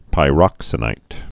(pī-rŏksə-nīt)